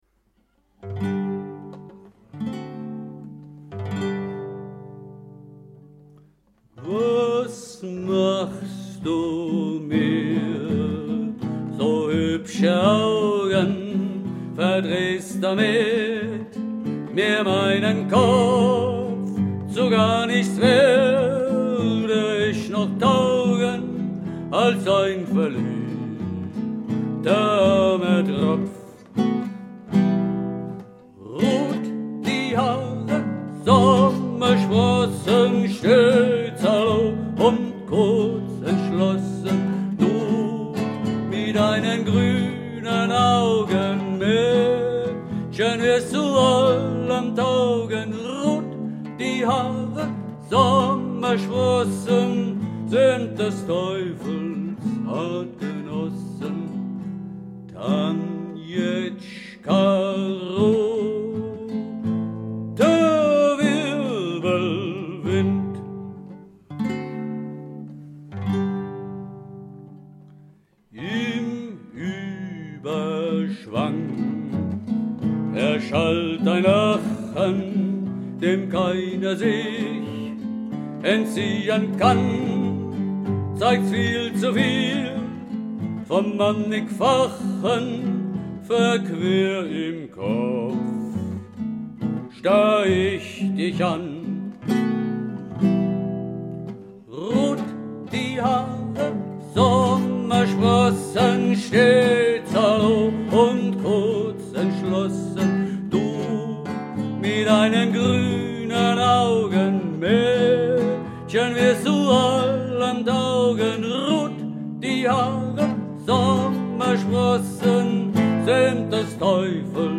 [Frühe Fassung]